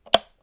switch18.wav